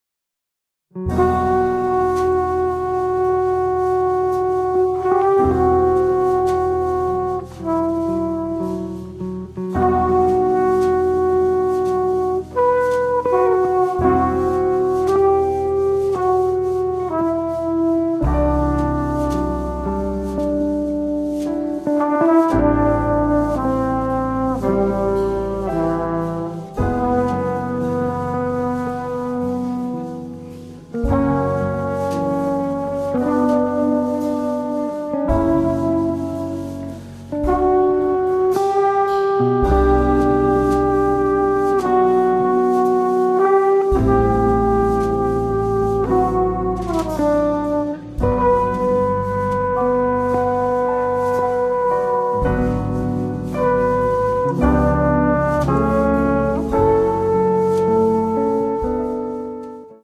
chitarra
sassofoni